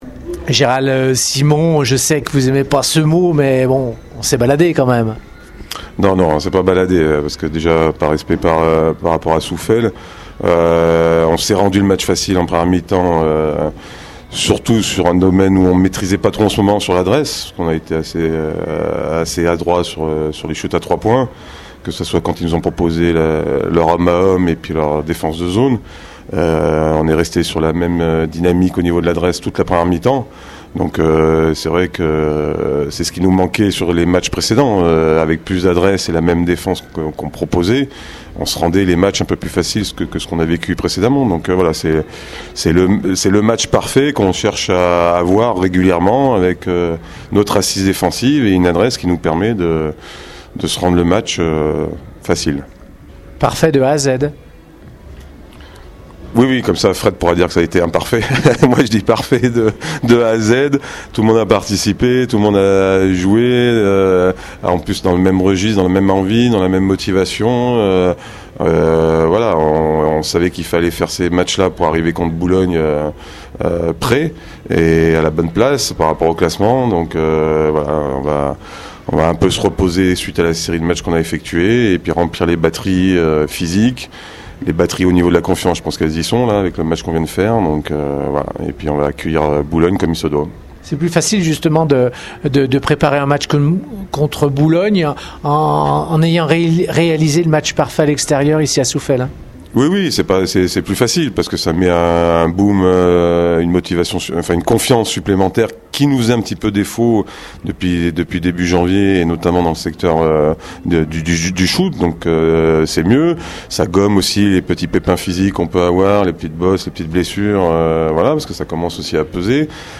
Retrouvez les réactions d’après-match au micro Radio Scoop